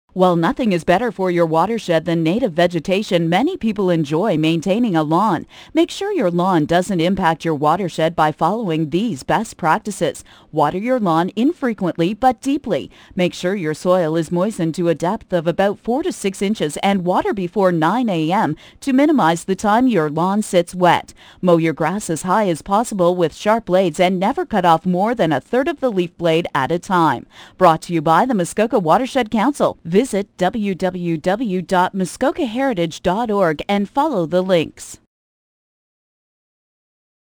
GardenPSA2-Moose2006.mp3